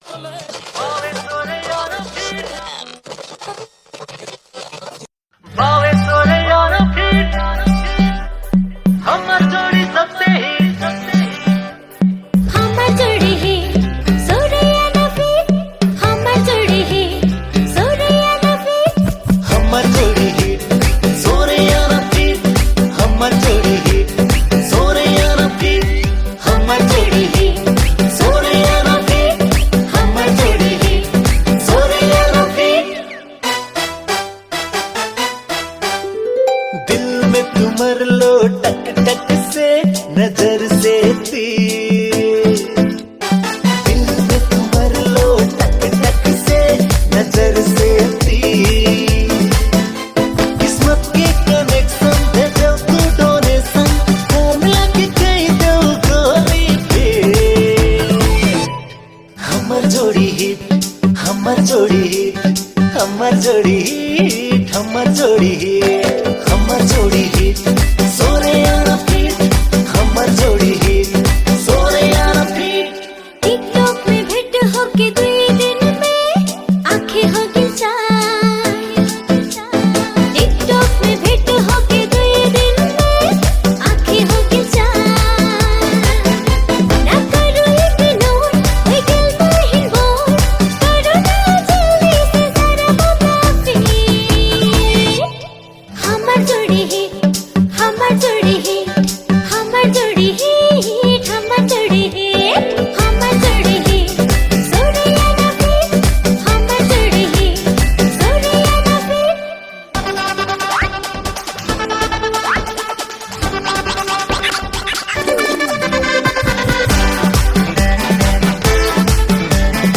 New Tharu Mp3 Song